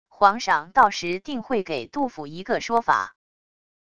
皇上到时定会给杜府一个说法wav音频生成系统WAV Audio Player